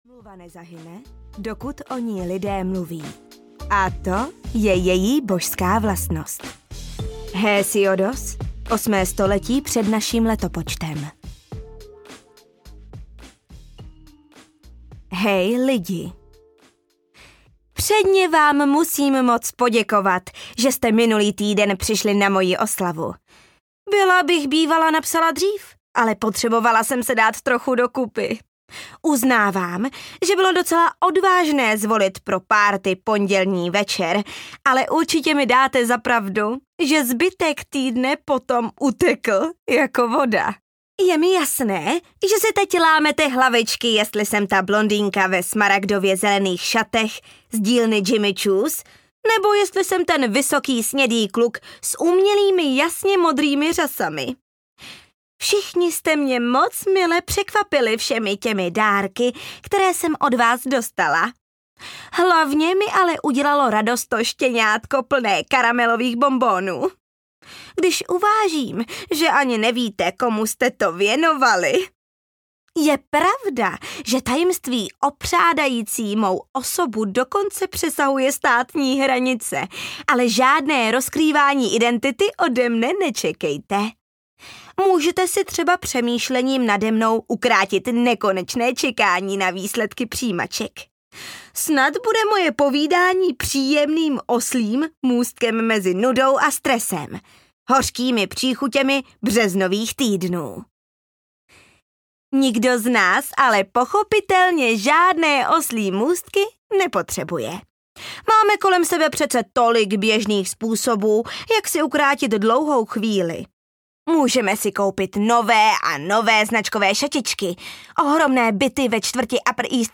Gossip Girl: Tak se mi to líbí audiokniha
Ukázka z knihy